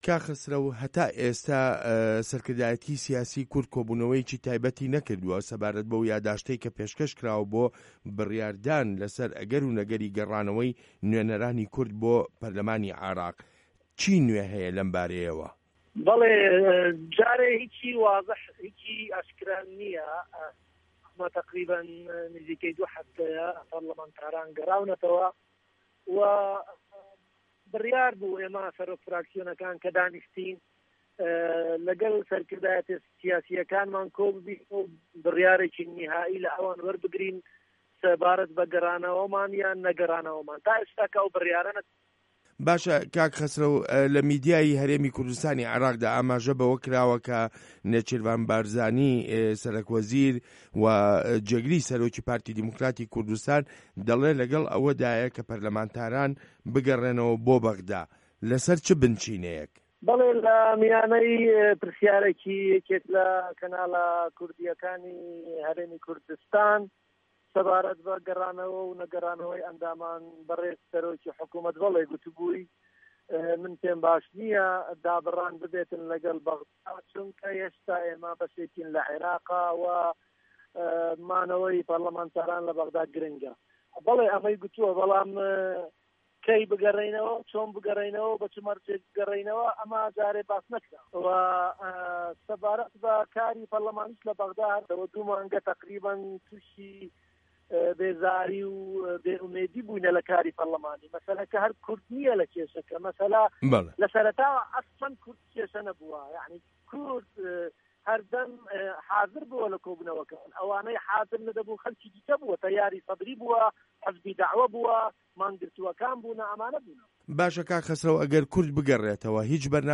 وتووێژ لەگەڵ خەسرەو گۆران